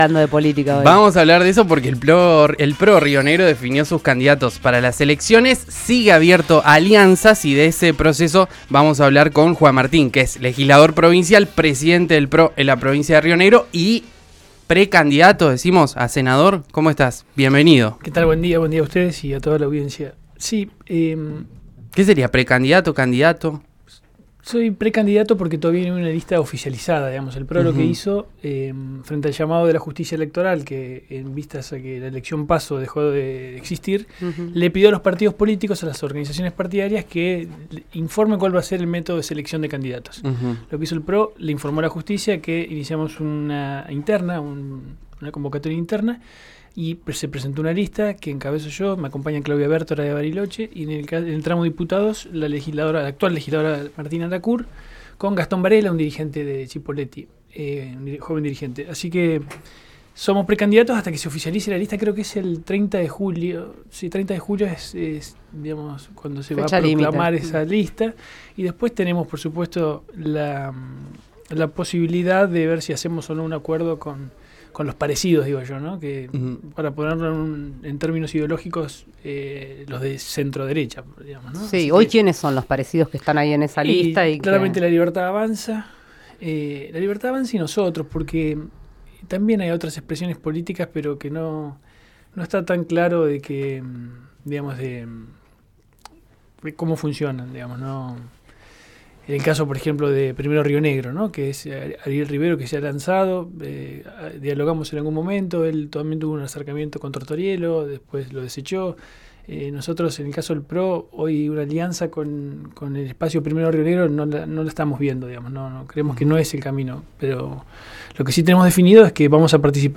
El líder del PRO en Río Negro, que también es candidato a senador, aseguró ante los micrófonos de RÍO NEGRO RADIO que por sintonía su partido y LLA podrían lograr una alianza, e incluso están abiertos a esa posibilidad.
Escuchá a Juan Martín en RÍO NEGRO RADIO